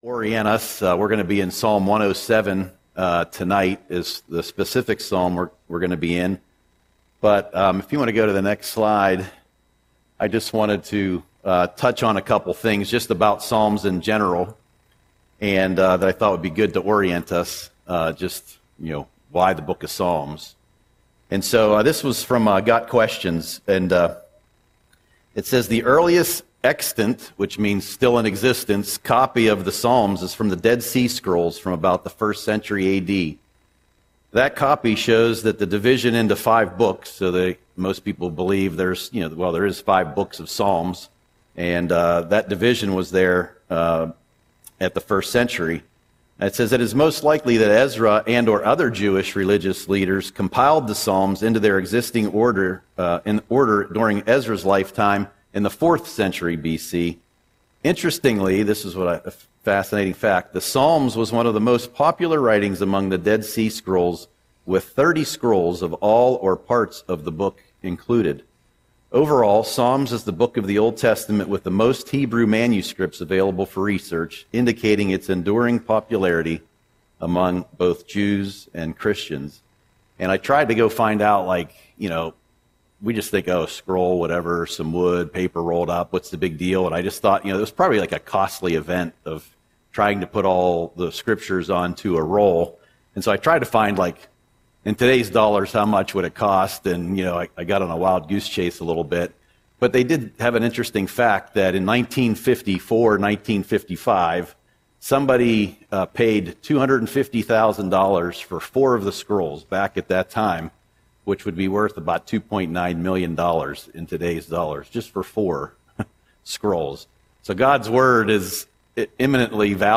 Audio Sermon - August 6, 2025